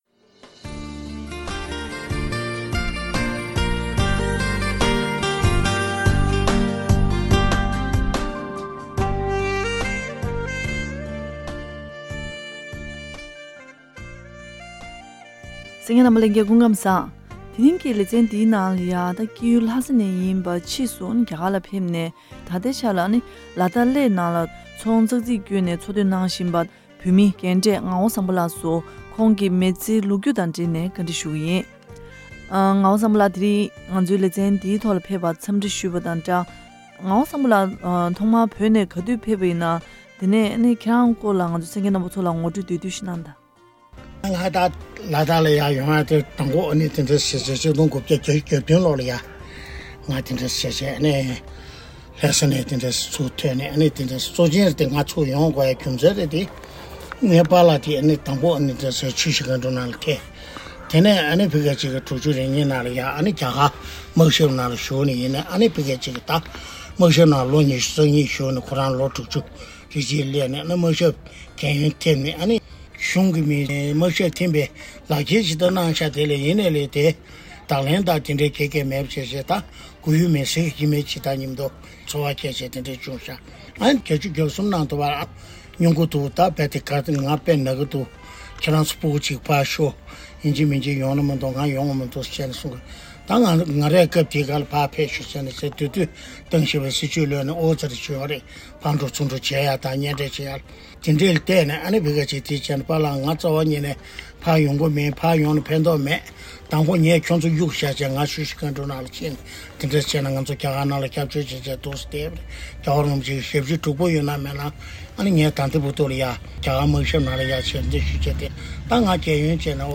བཀའ་དྲི་ཞུས་པ་ཞིག་གསན་གནང་གི་རེད།